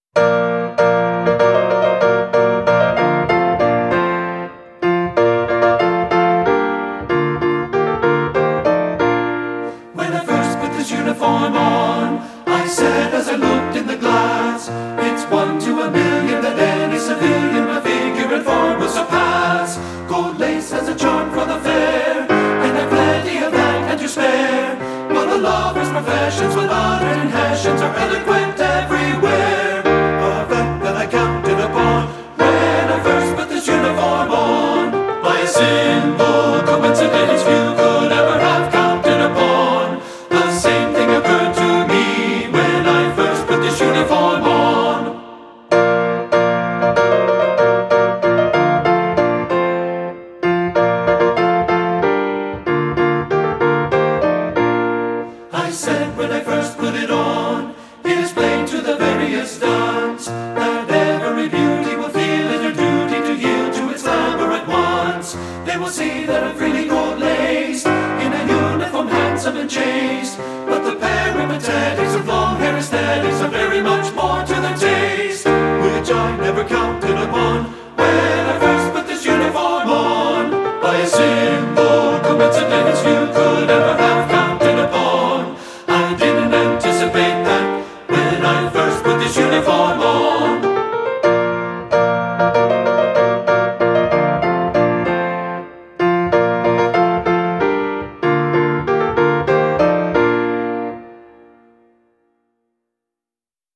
Voicing: TT(B) and Piano